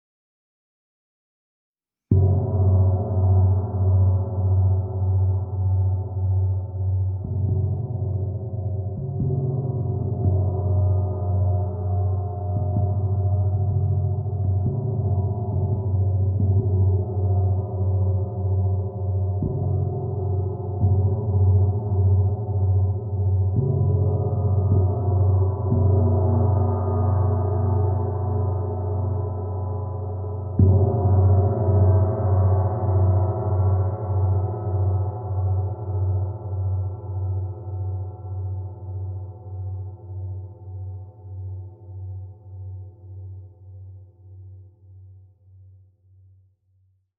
Meinl Sonic Energy 22" Soundscape Gong, Flower of Life (SGFOL22)
Feature: Rich, dynamic soundscapeFeature: Polished surface for a luxurious shineFeature: Easily playable edgeFeature: High-quality engraved symbolFea…